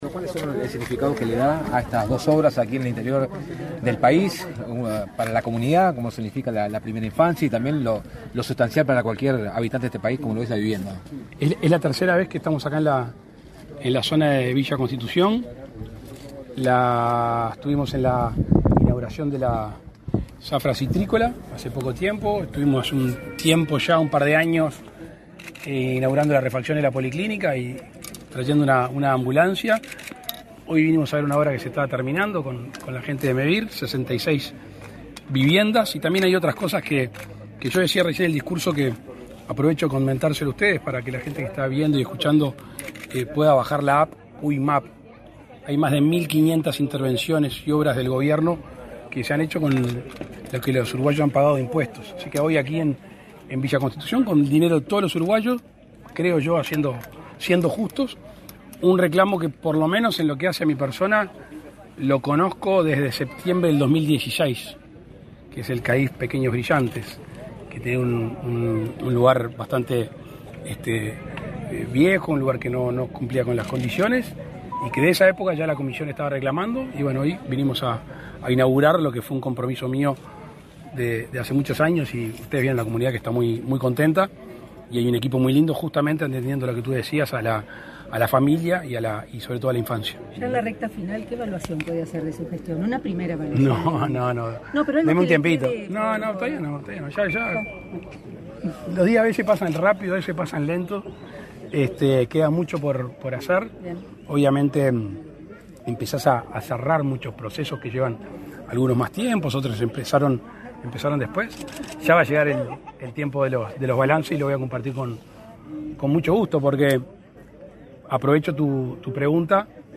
Declaraciones a la prensa del presidente de la República, Luis Lacalle Pou
Declaraciones a la prensa del presidente de la República, Luis Lacalle Pou 05/09/2024 Compartir Facebook X Copiar enlace WhatsApp LinkedIn Tras participar en la recorrida de un plan de Mevir en la localidad de Villa Constitución, en Salto, este 5 de setiembre, el presidente de la República, Luis Lacalle Pou, realizó declaraciones a la prensa.